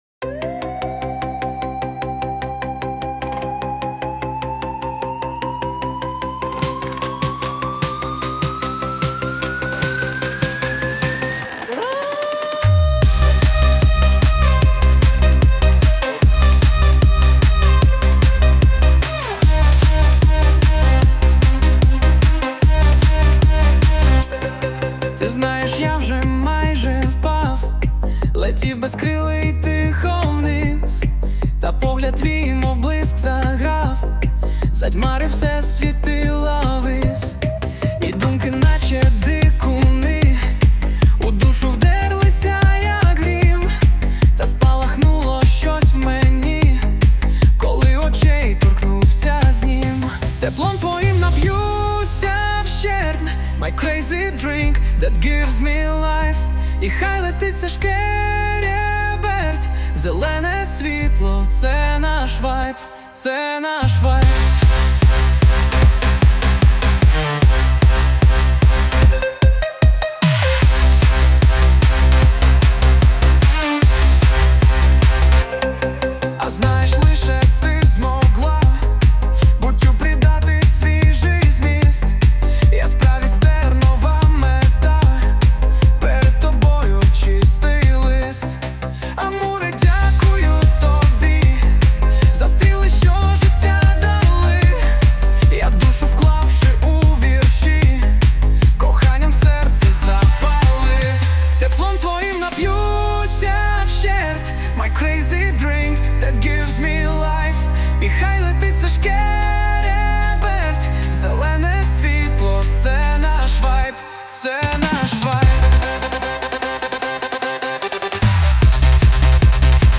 Музика та голос =SUNO
СТИЛЬОВІ ЖАНРИ: Ліричний
ВИД ТВОРУ: Пісня